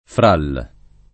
fra il [fr# il] (poet. fra ’l [